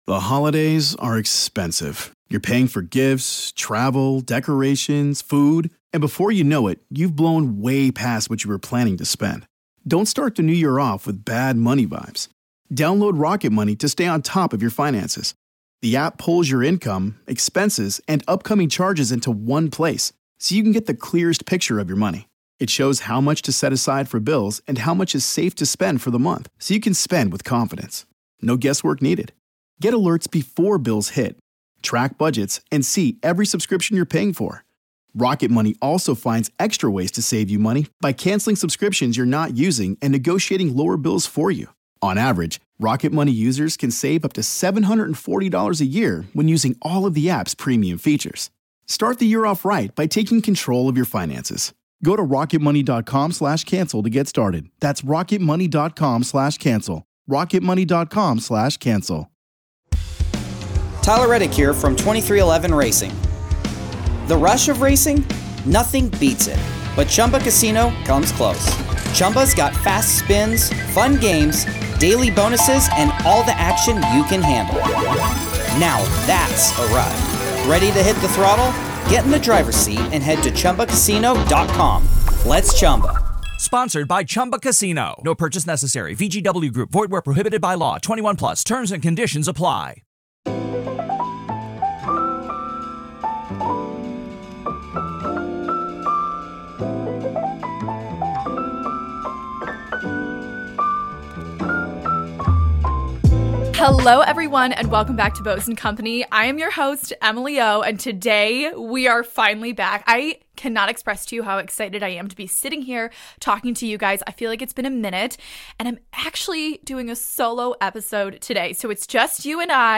My first solo episode ever, how fun! I share an in depth review of my fall capsule wardrobe!